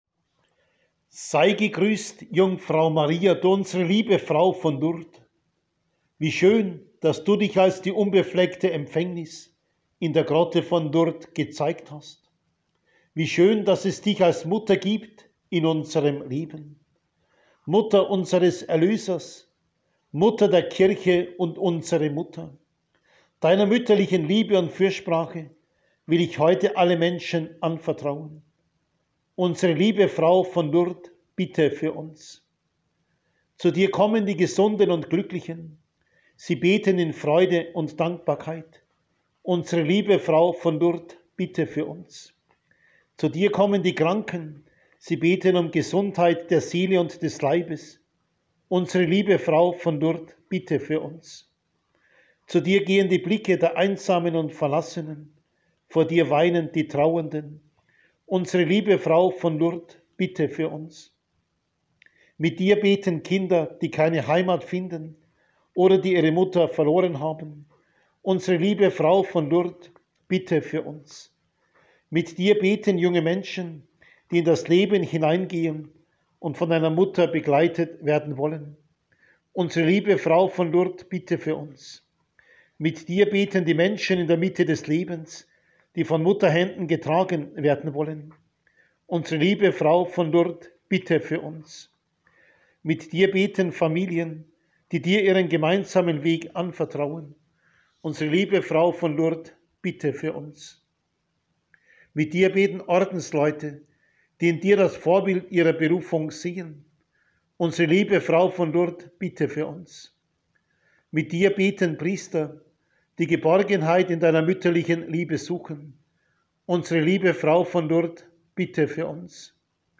Marienandacht an der Fockenfelder Lourdesgrotte
Maiandacht2021.mp3